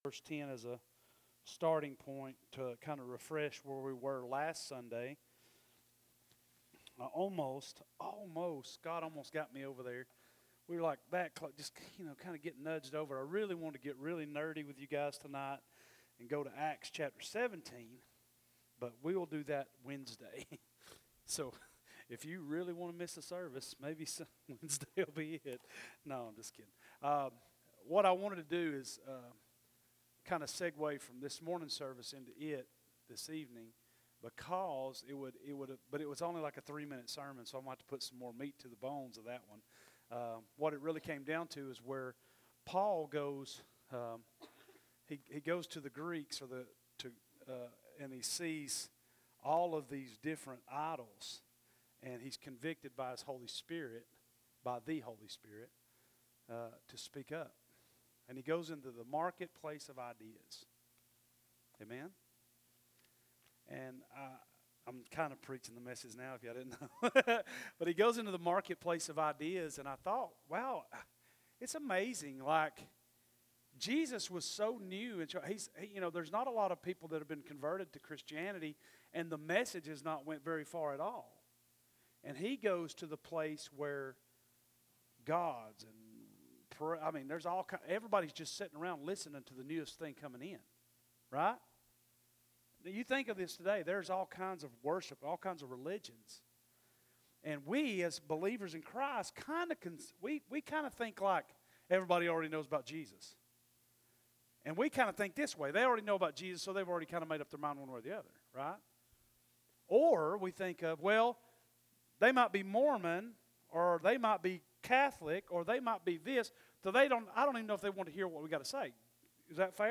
Sermons Archive
Category: Sunday Morning